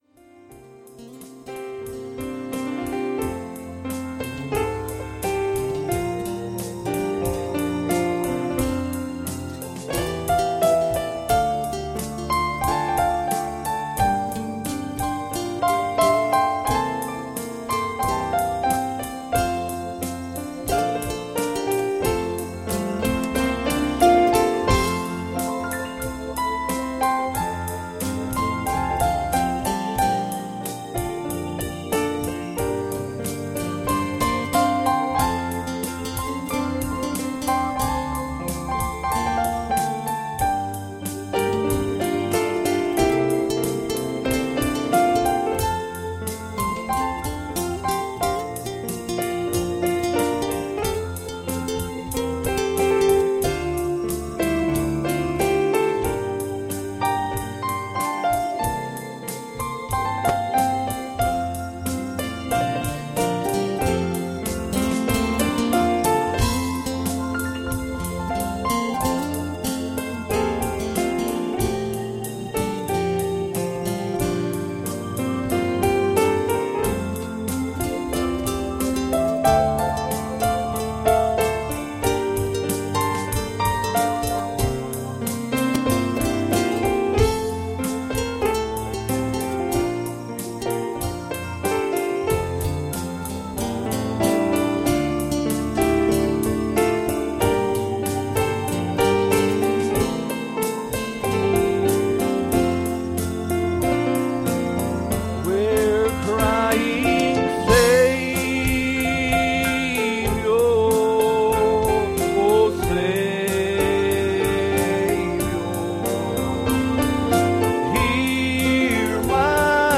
Psalm 27:15 Service Type: Sunday Morning "See